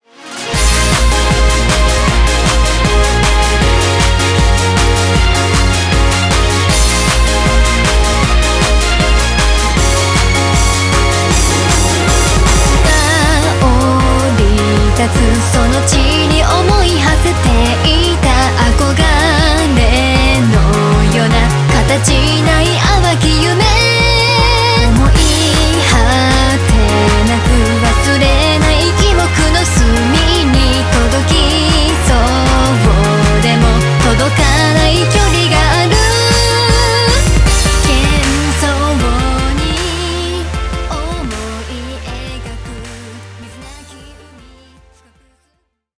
TRANCE ROCK
Remix